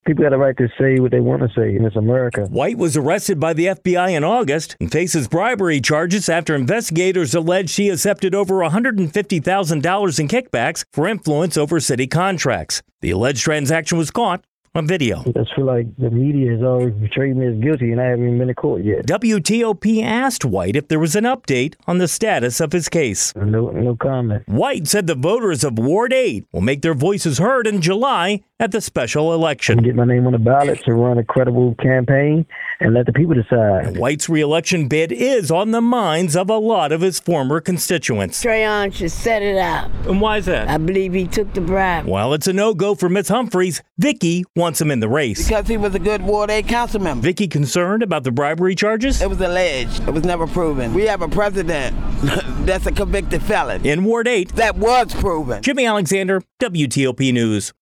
Most DC residents tell WTOP they support former DC Council Member Trayon White's election campaign.